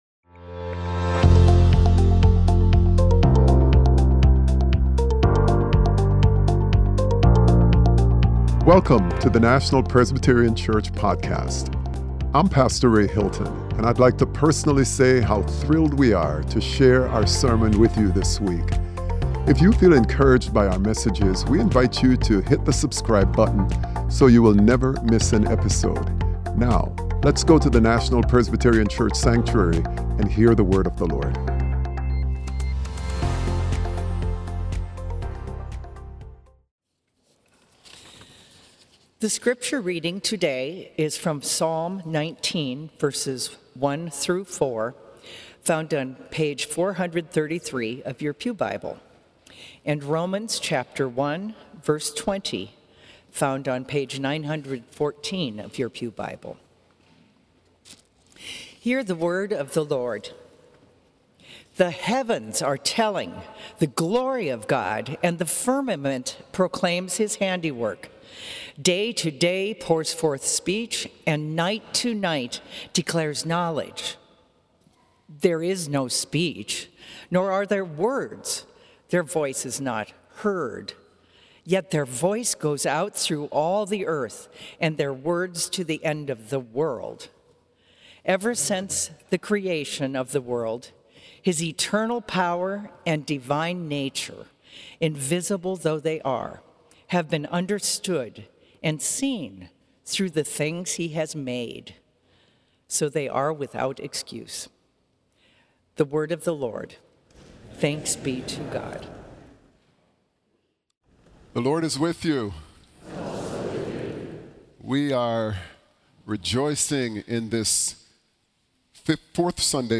Sermon: Encountering God More Deeply - When Creation Preaches - National Presbyterian Church